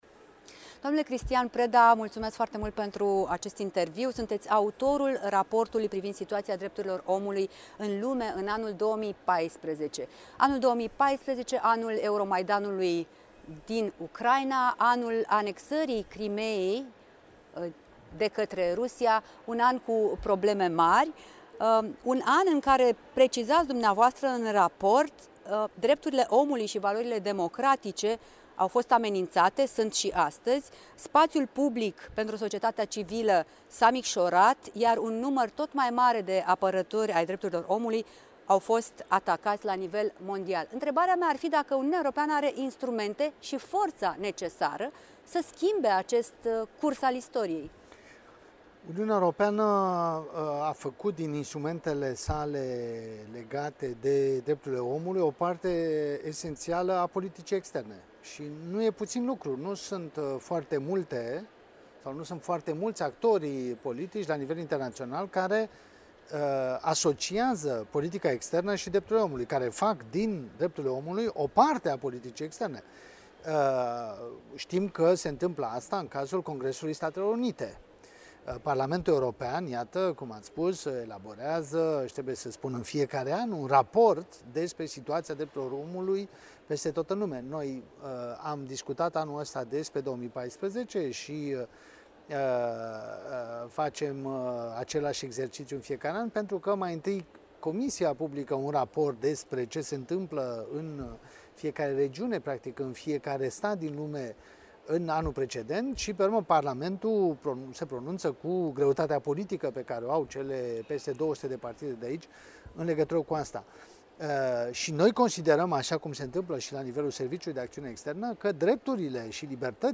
Un interviu în exclusivitate